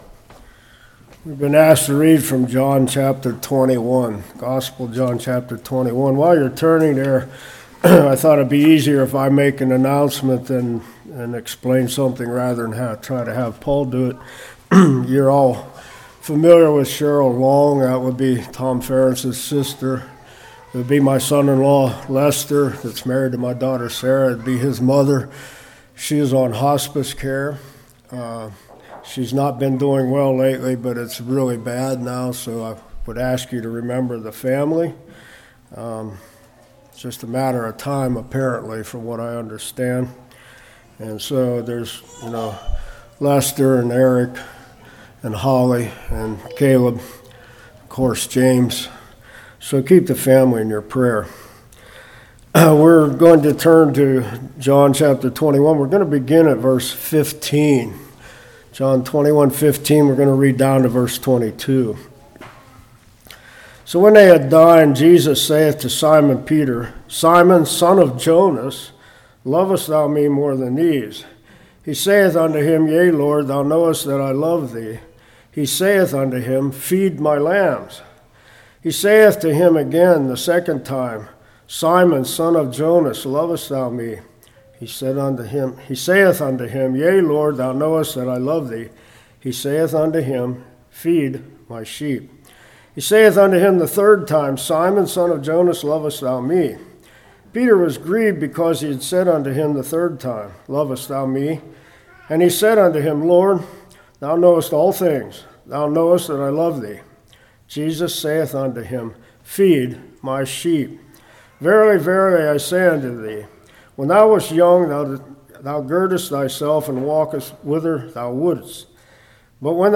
John 21:15-22 Service Type: Revival What was Jesus asking?